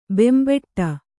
♪ bembaṭṭa